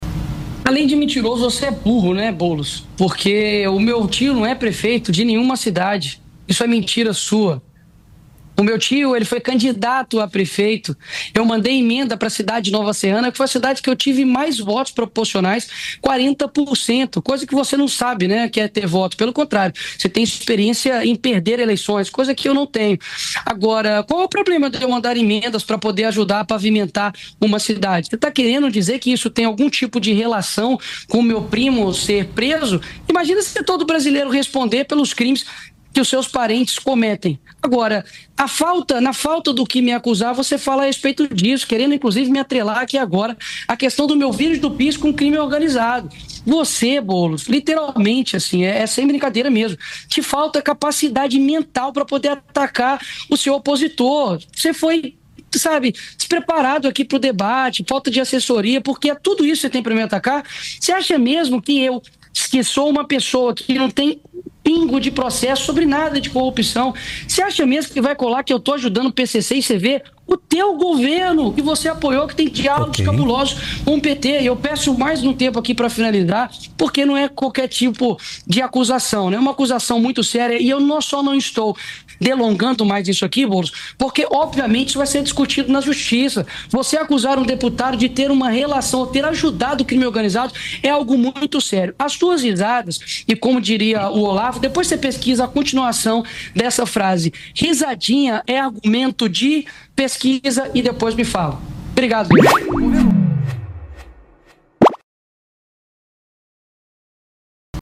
A troca de acusações elevou o tom do confronto entre os parlamentares ao vivo.